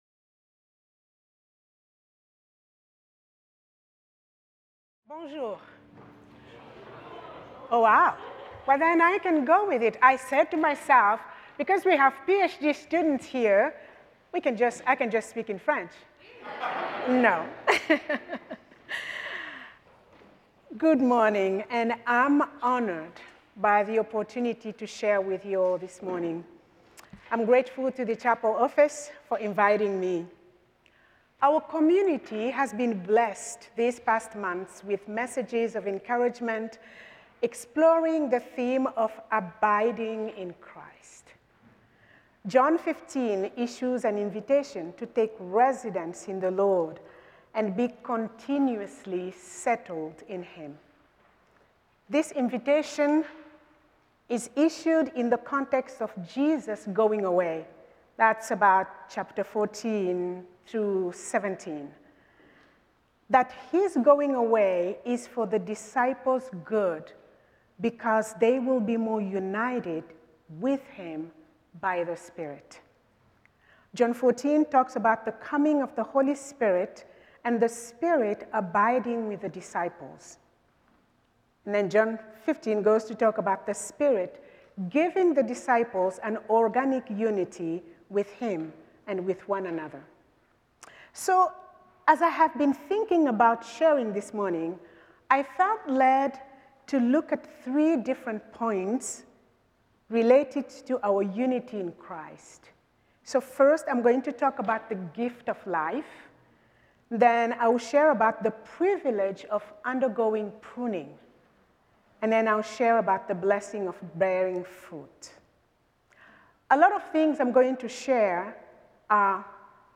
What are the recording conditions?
The following service took place on Thursday, February 5, 2026.